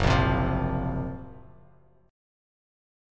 F#M#11 chord